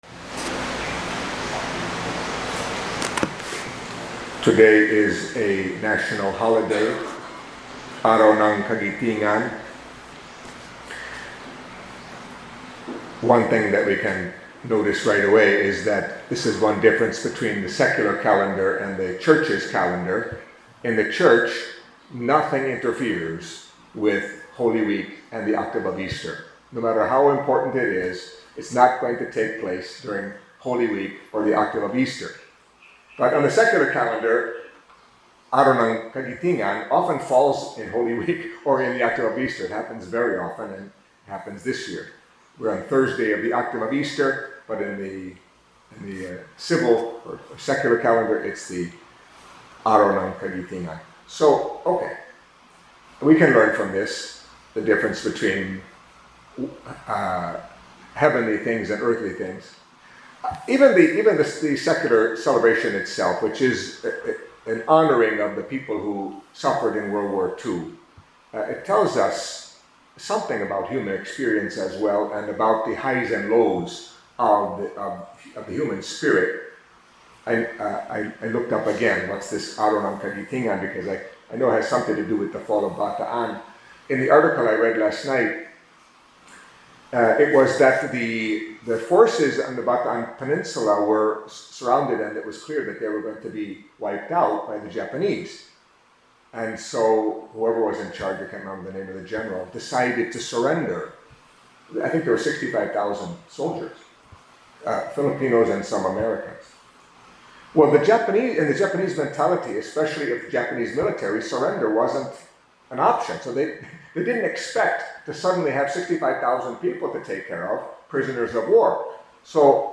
Catholic Mass homily for Thursday in the Octave of Easter